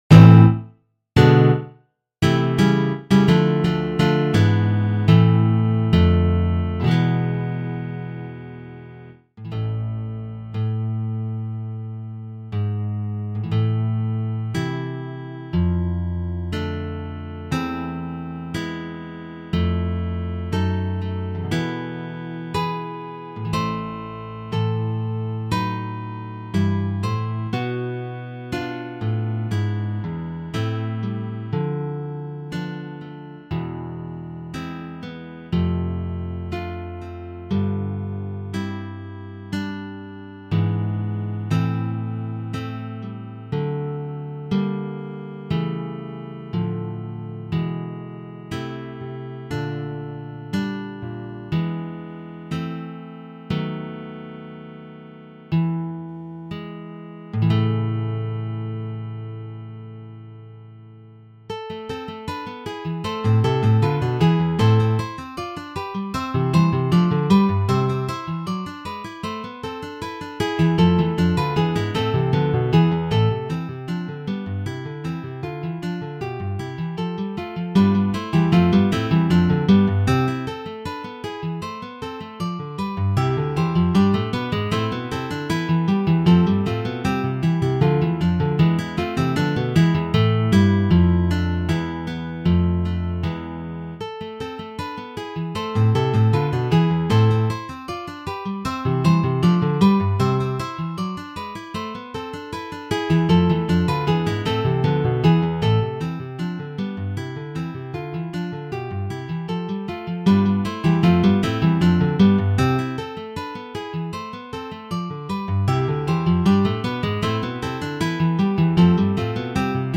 “with 4 soloists”